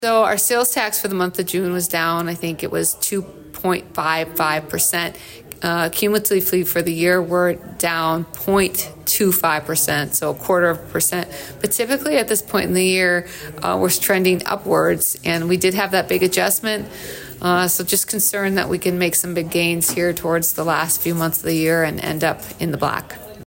City Manager Amy Leon says that sales tax revenue to date is down from last year, and it’s a common thread in the state.